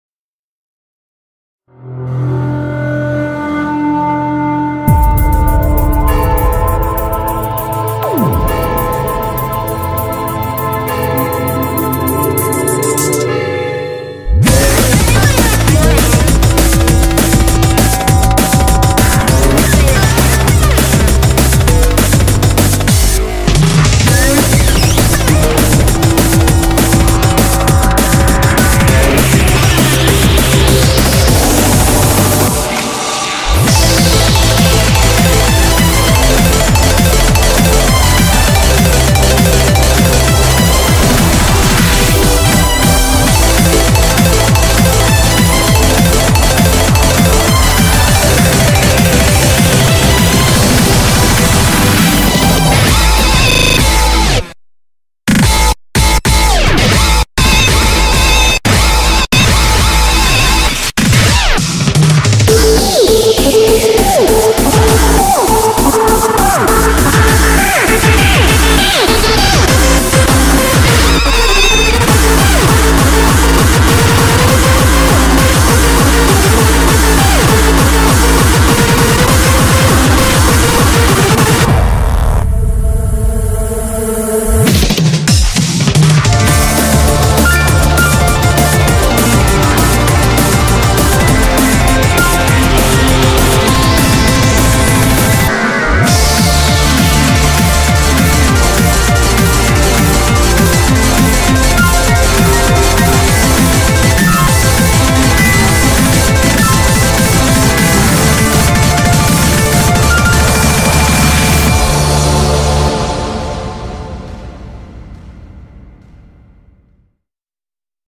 BPM200--1
Audio QualityPerfect (High Quality)